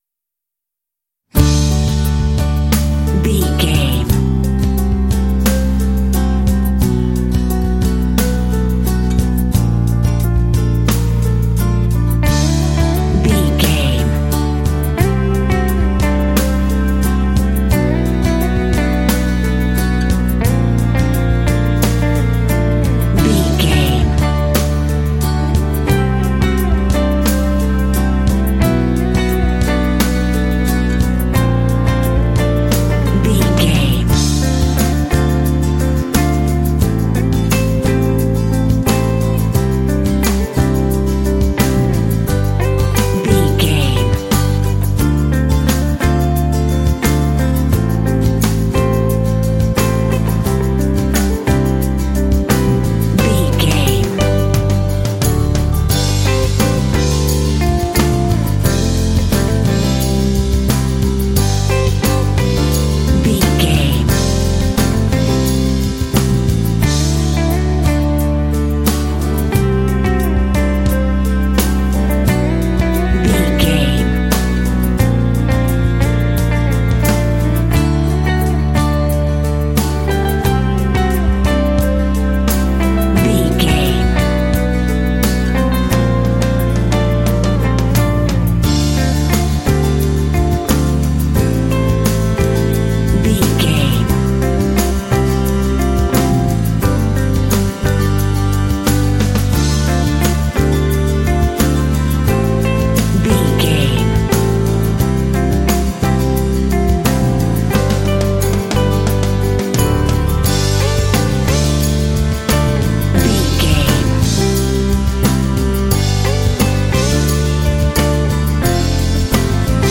Ionian/Major
light
dreamy
sweet
orchestra
horns
strings
percussion
cello
acoustic guitar
cinematic
pop